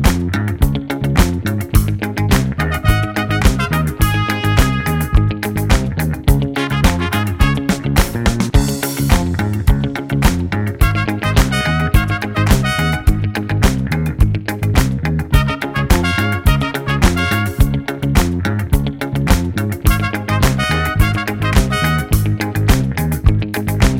no bass Disco 4:02 Buy £1.50